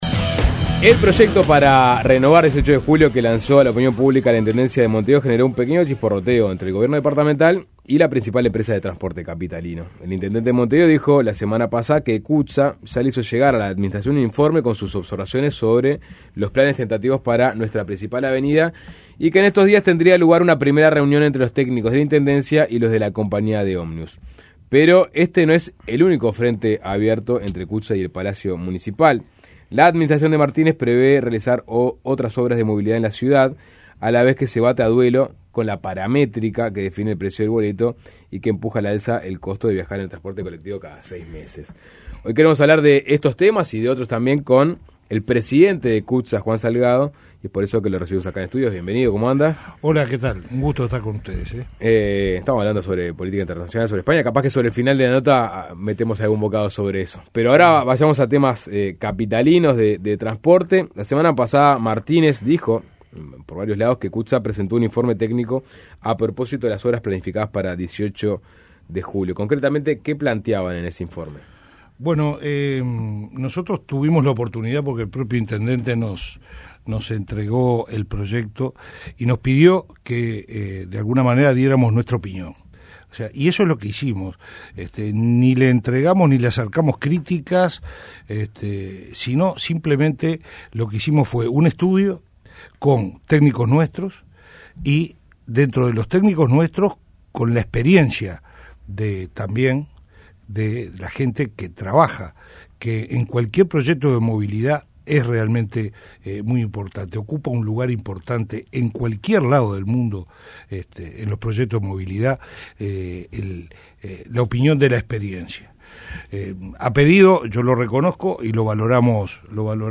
Descargar Audio no soportado En entrevista con Suena Tremendo